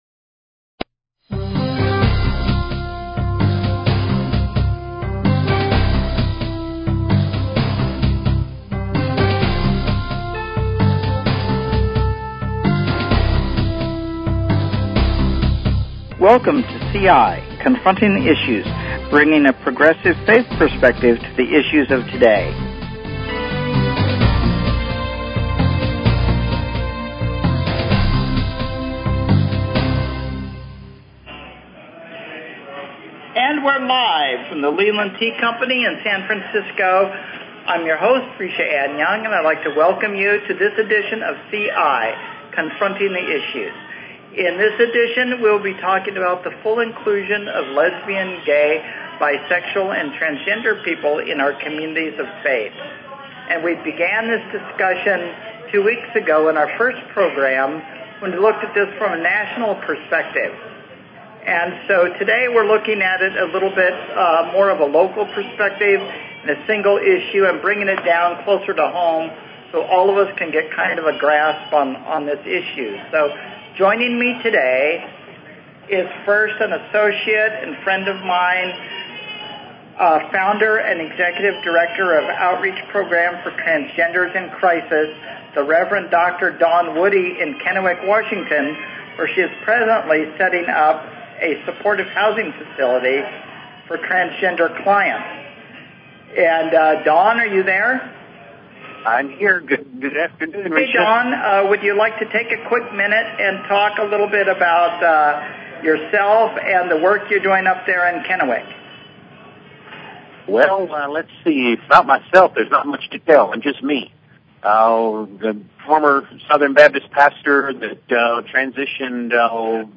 Talk Show Episode, Audio Podcast, CI_Confronting_the_Issues and Courtesy of BBS Radio on , show guests , about , categorized as
Live from the Leland Tea Company, in San Francisco. Bringing a progressive faith perspective to the issues of the day.